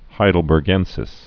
(hīdəl-bûr-gĕnsĭs)